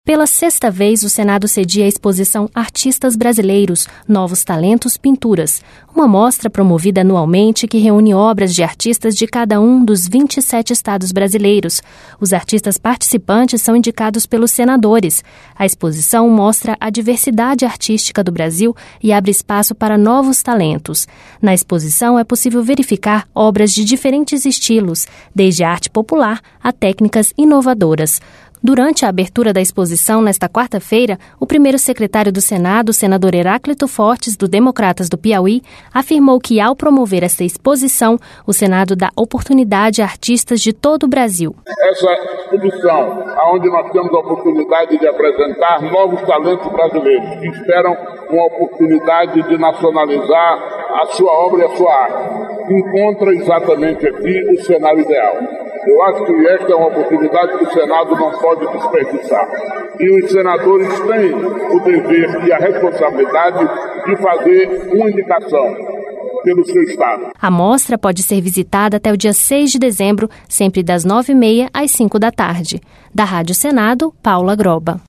Durante a abertura da exposição, nesta quarta-feira, o primeiro-secretário do Senado, senador Heráclito Fortes, do Democratas do Piauí, afirmou que ao promover esta exposição, o Senado dá oportunidade a artistas de todo o Brasil.